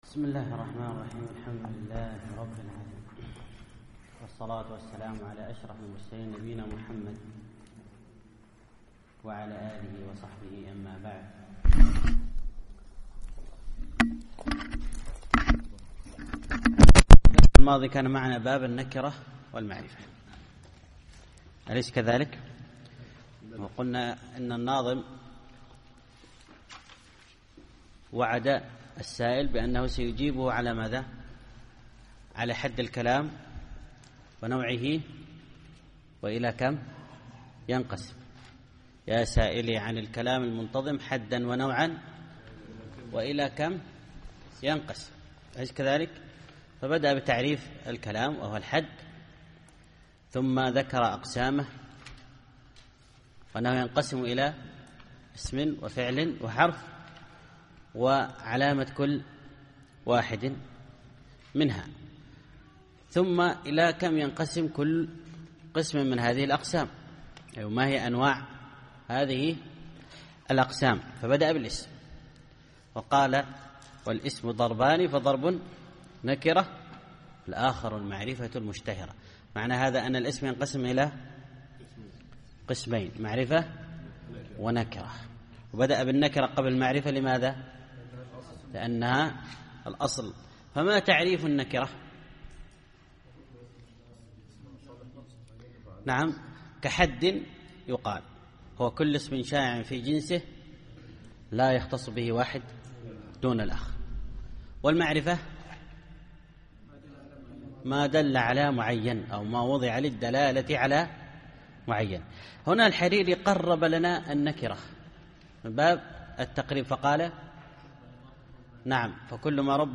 الدرس الثالث الأبيات 33-51